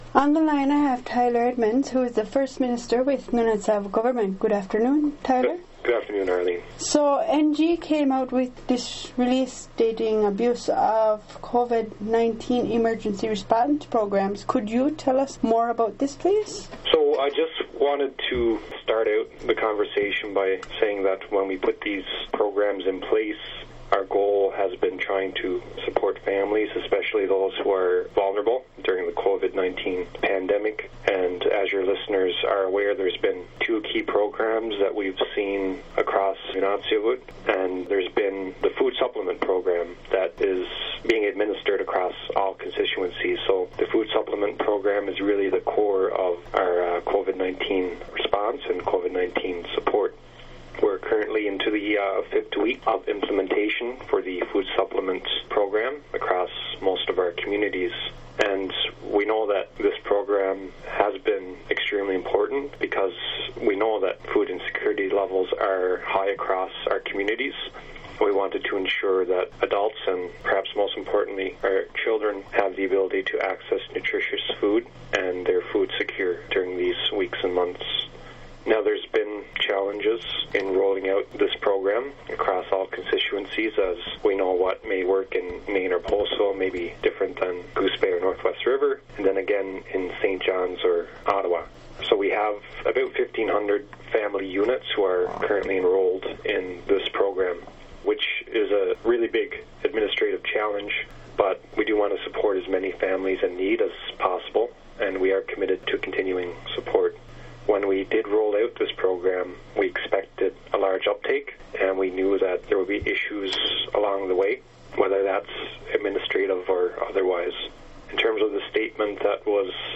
here when First Minister Tyler Edmunds gives you more details on the abuse of the Covid-19 emergency programs.